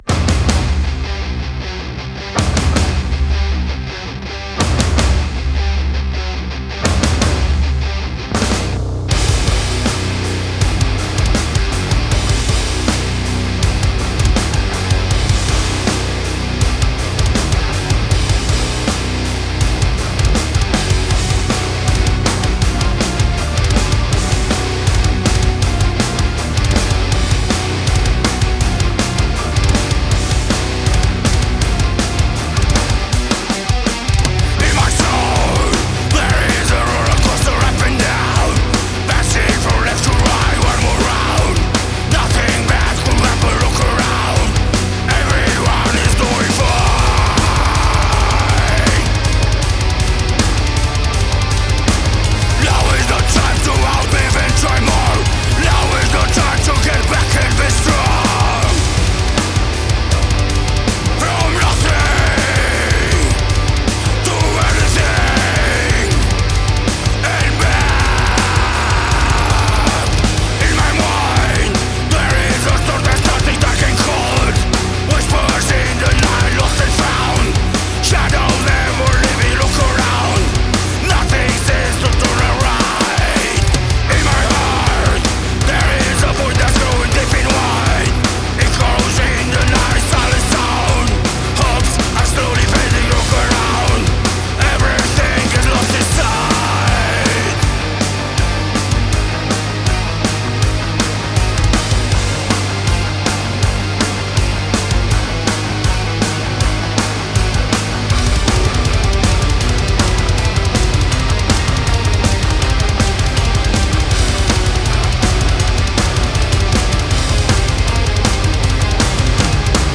chitară, voce
bass, voce
keyboard